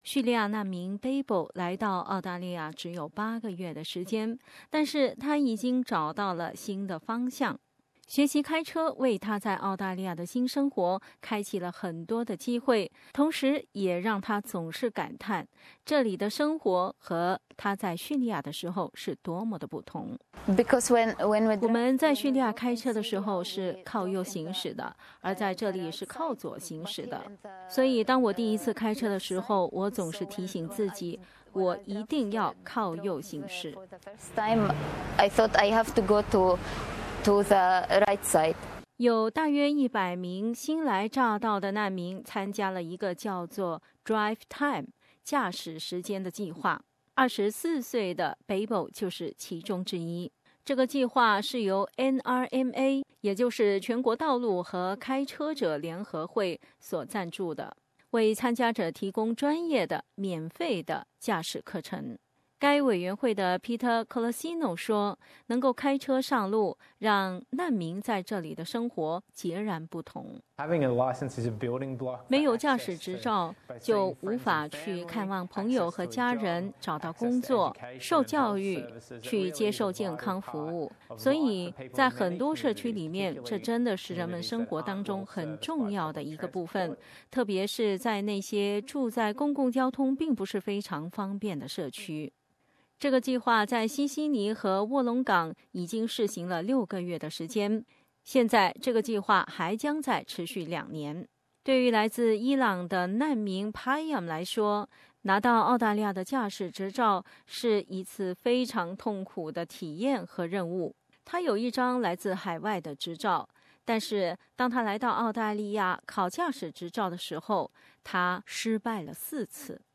来到澳大利亚，却无法开车，对于很多难民来说，很难开始新生活。 西悉尼郊区有一个免费教难民开车的计划，帮助了初来乍到的难民得到免费的、正规的驾驶培训，并拿到车证，下面我们来听听受到帮助的人们的现身说法。